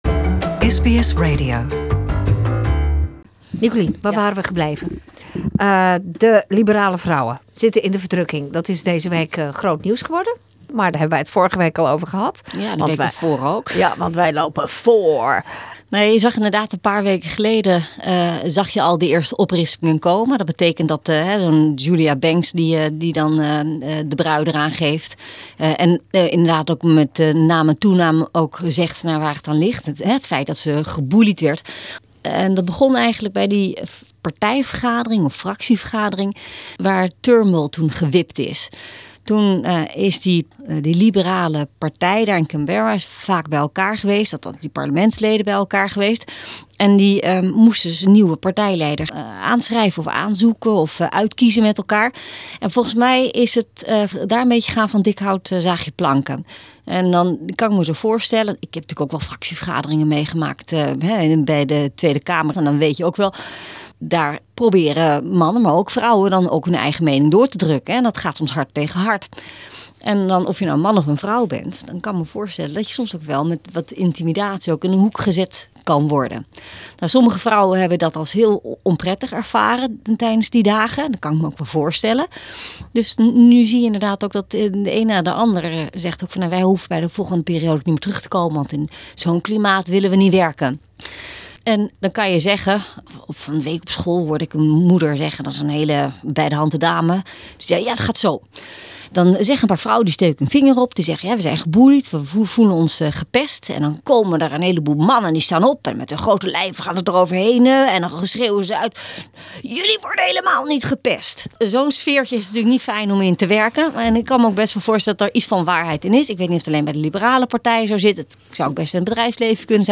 Special reporter for Political Affairs, Dutch ex-parliamentarian Nicolien van Vroonhoven, sheds her light this week on why the remaining women in parliament wore red, if the aged care industry is just as troubled in the Netherlands as it is here, and why the private and the independent schools suddenly received so much funding.